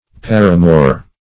Paramour \Par"a*mour\, n. [F. par amour, lit., by or with love.